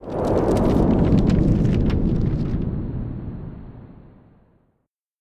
fire_blowing.ogg